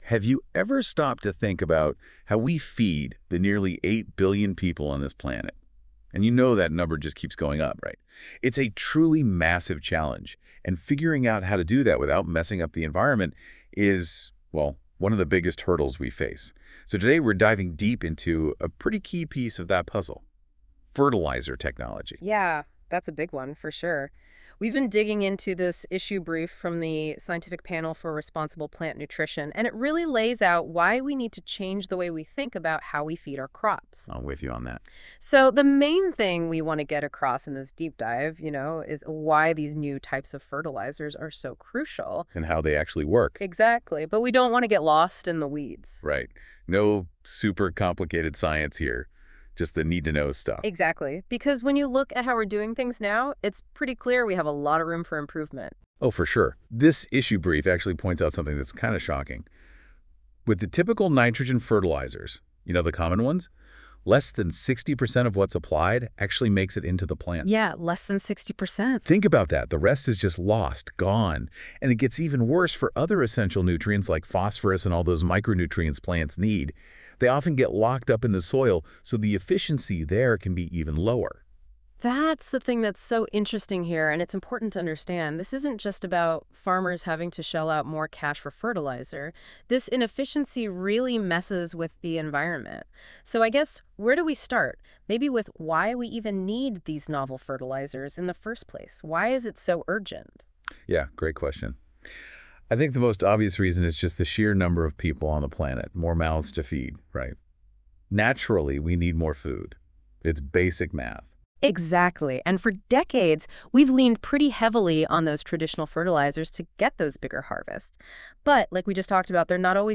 Listen to an AI-generated explainer audio